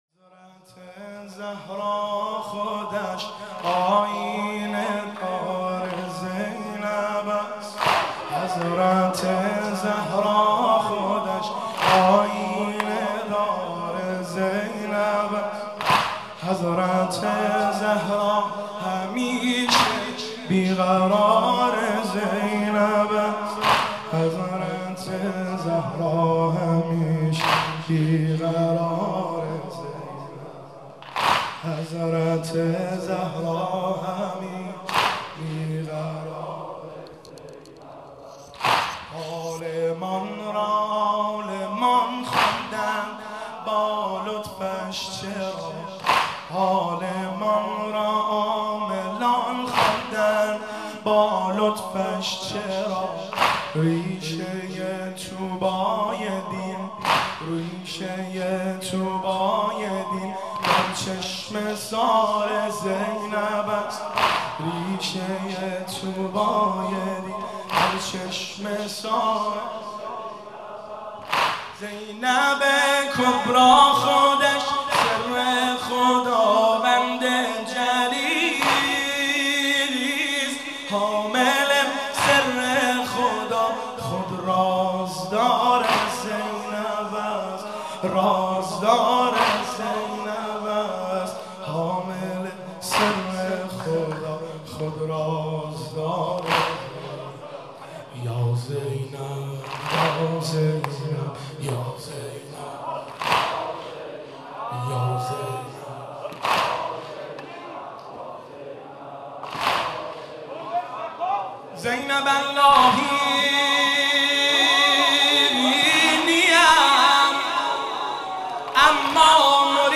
تو به شکل گل یاسی ، هنرت خداشناسی ( شور )
سلام من به زهرای زمین کربلا زینب ( واحد )